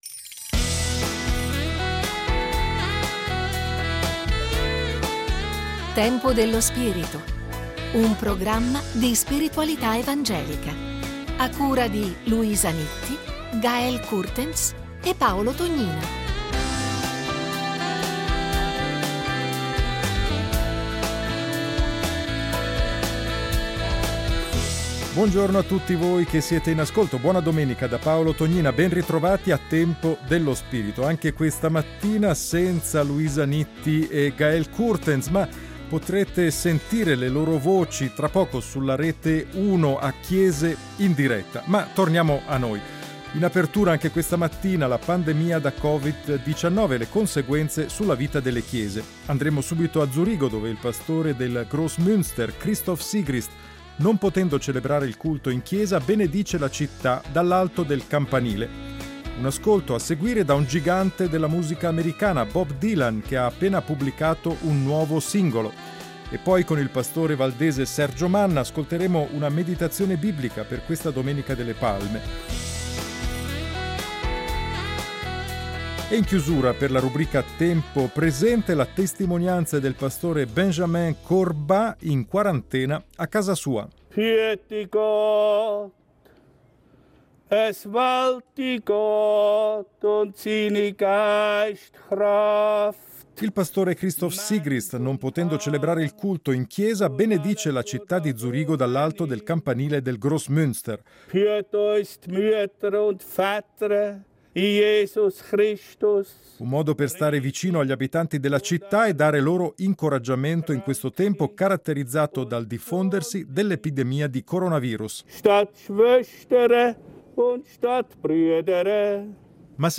Estratto Segni dei Tempi RSI La1, 04.04.2020
Per la Domenica delle Palme, una meditazione biblica